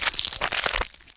rifle_eyestalk3.wav